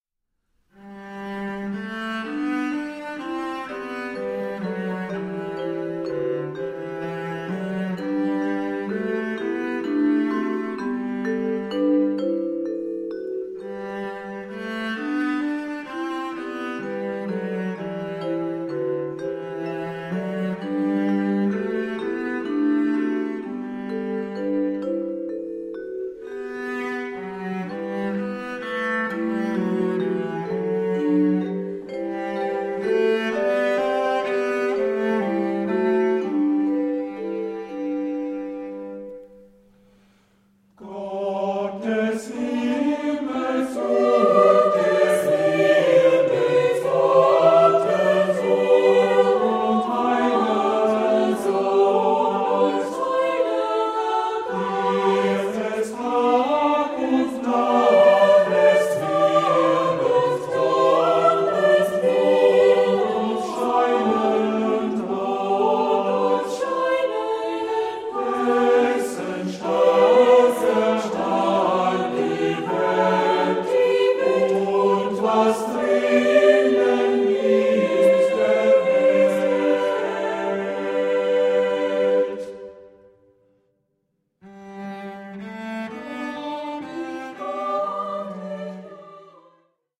• Sachgebiet: Chormusik/Evangeliumslieder
• Morgen und Abendlieder